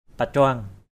/pa-cʊaŋ/ (đg.) đặt tréo = croiser (qq. ch.).